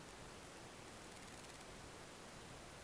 Static and/or high pitch hum